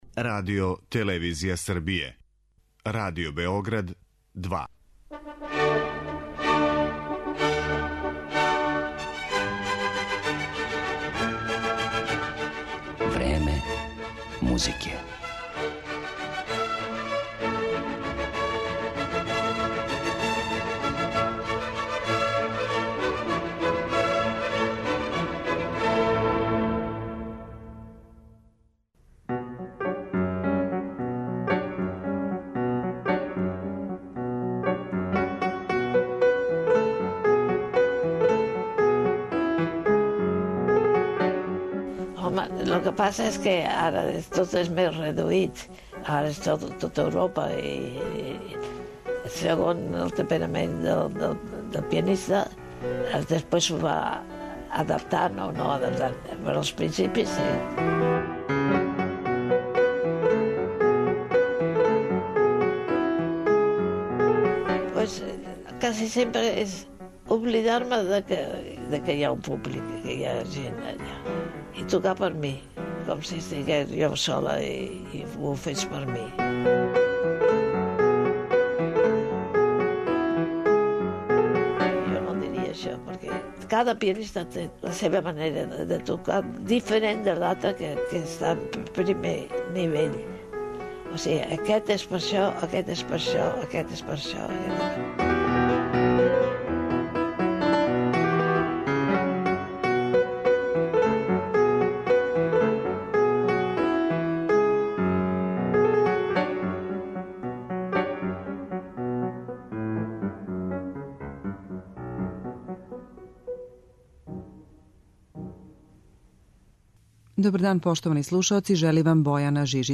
Емисија посвећена пијанисткињи Алисији де Лароћа
Славна шпанска уметница ће изводити композиције Гранадоса, Де Фаље, Албениза, Монсалвађа и Моцарта.